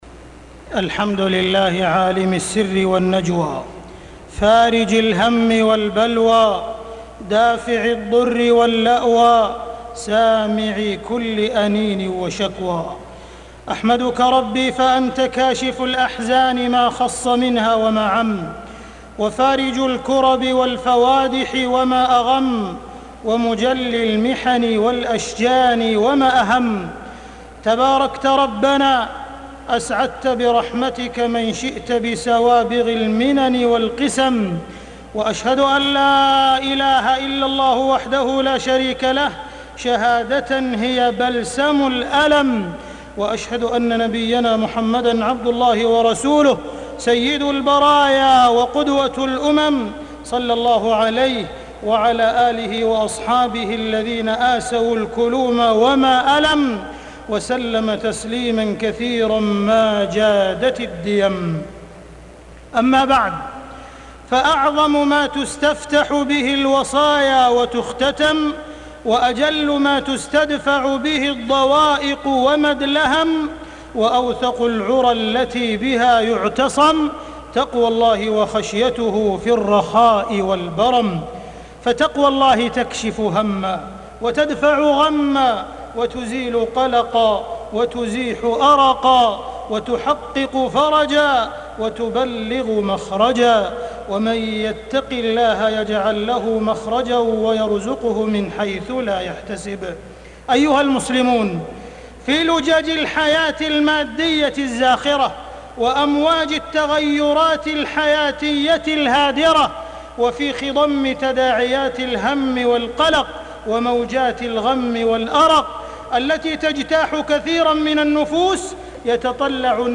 تاريخ النشر ١٤ ربيع الثاني ١٤٢٧ هـ المكان: المسجد الحرام الشيخ: معالي الشيخ أ.د. عبدالرحمن بن عبدالعزيز السديس معالي الشيخ أ.د. عبدالرحمن بن عبدالعزيز السديس تبديد كدر الأرق والقلق والفكر